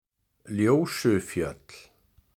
Ljósufjöll (Icelandic pronunciation: [ˈljouːsʏˌfjœtl̥]
Ljósufjöll_pronunciation.ogg.mp3